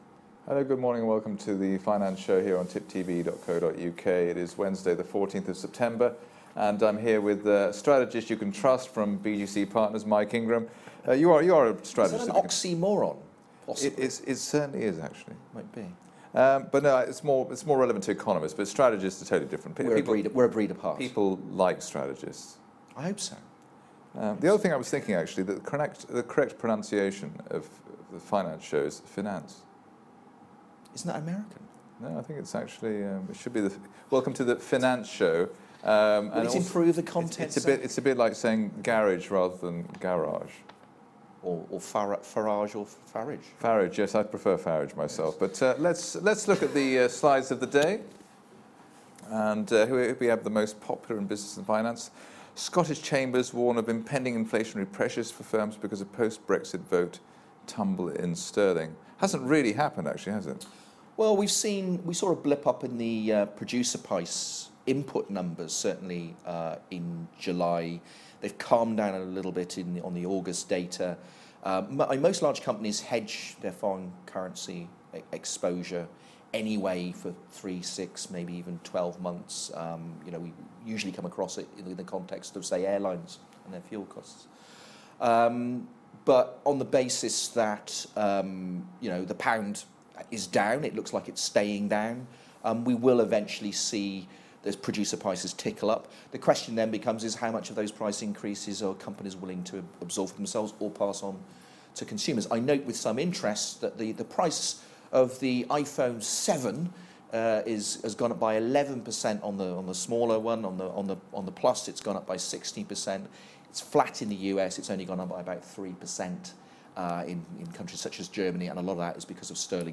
Watch the today’s opening segment of the London open show to know more about the possible trajectory of inflation over the next few months.